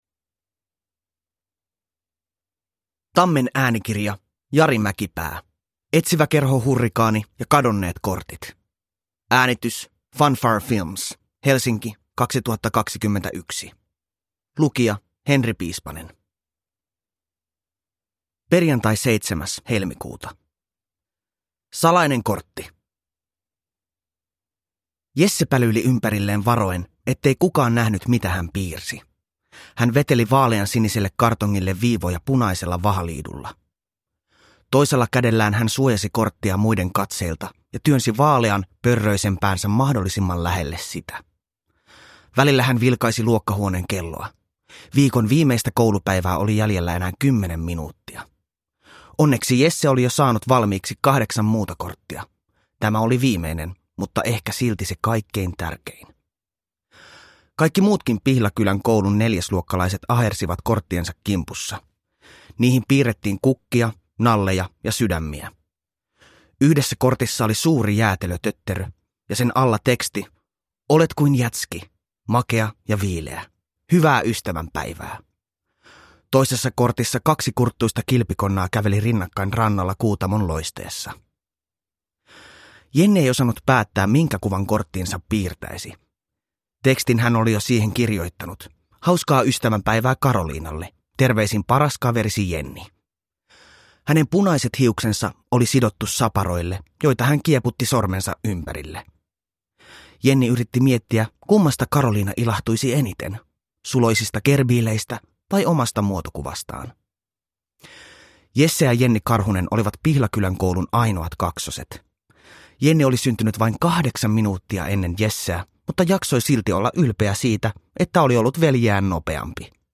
Etsiväkerho Hurrikaani ja kadonneet kortit – Ljudbok – Laddas ner